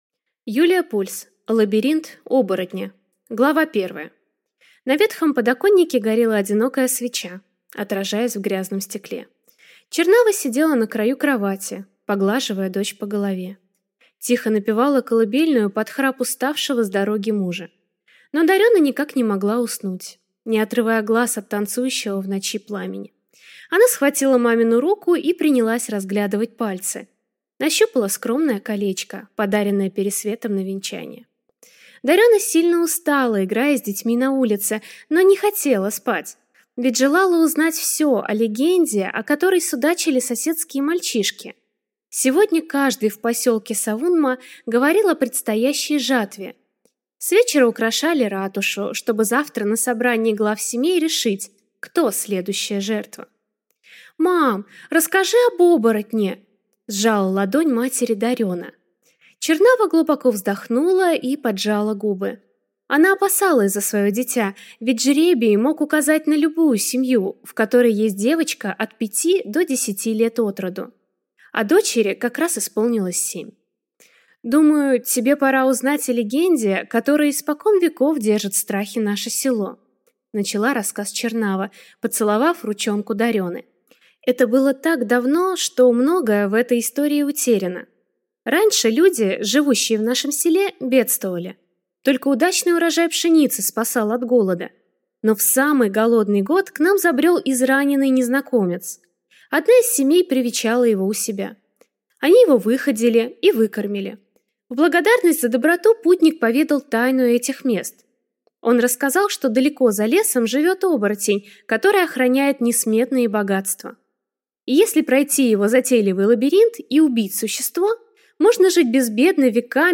Аудиокнига Лабиринт оборотня | Библиотека аудиокниг